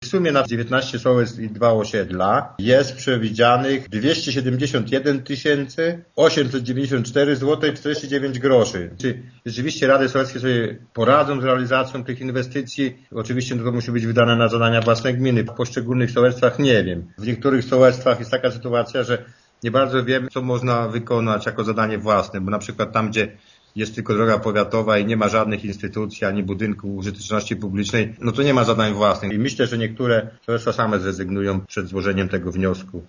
Zdaniem wójta Jana Filipczaka niektóre nie będą mogły tego zrobić ze względów formalnych: